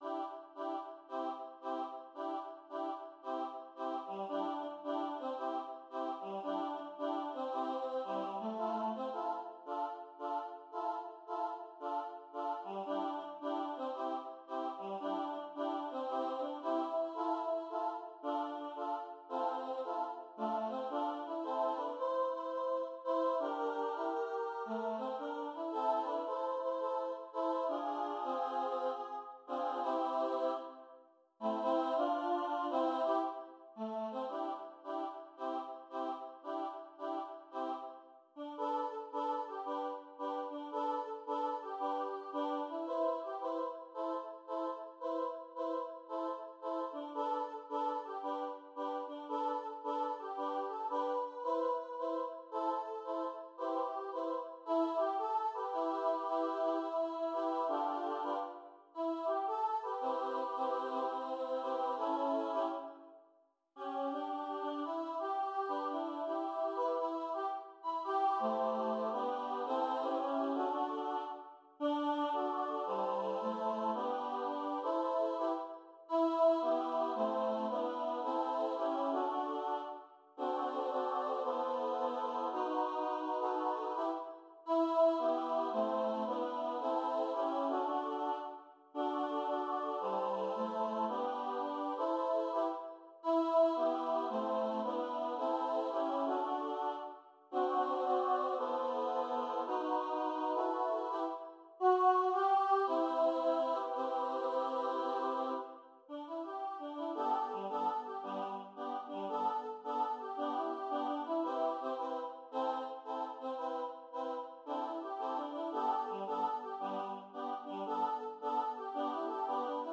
som kan synges både a cappella og med akkompagnement